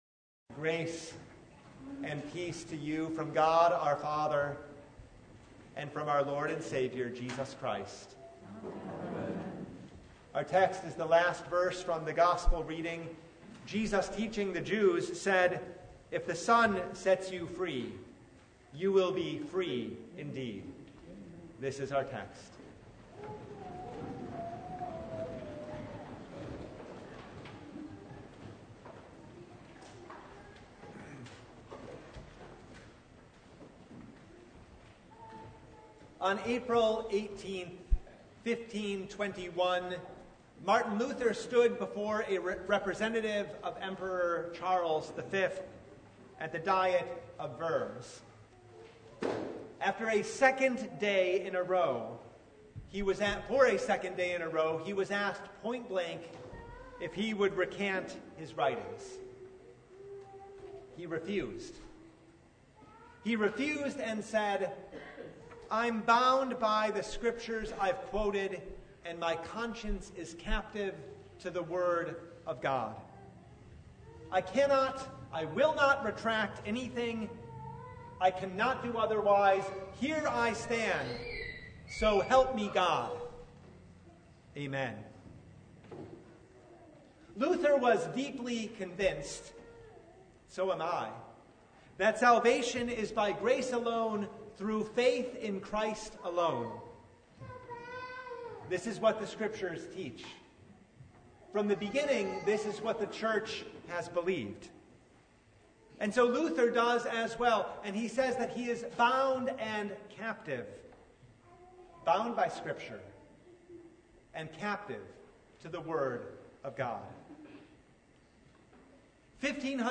John 8:31–36 Service Type: The Festival of the Reformation By nature